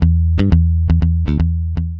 Trap WOB Bass 001 120BPM
描述：摇摆不定的低音循环。
标签： 120 bpm Trap Loops Bass Wobble Loops 2.69 MB wav Key : Unknown Mixcraft
声道立体声